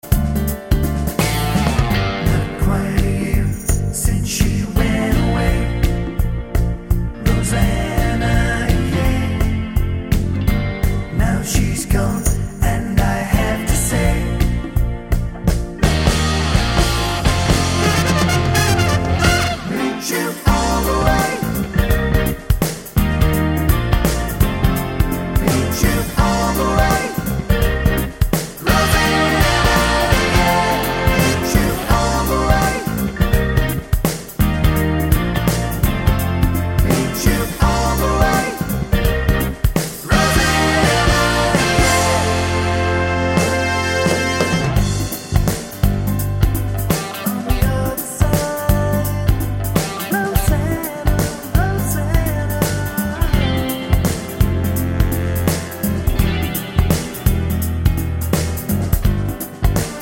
no Backing Vocals Soft Rock 4:36 Buy £1.50